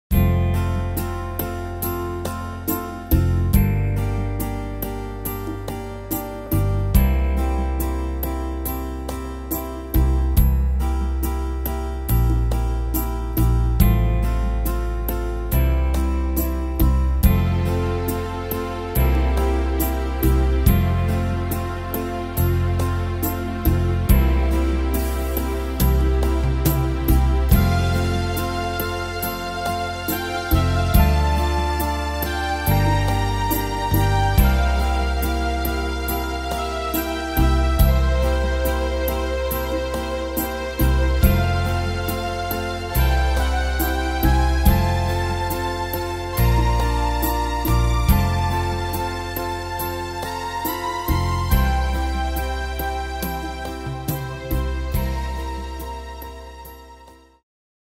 Tempo: 70 / Tonart: F-Dur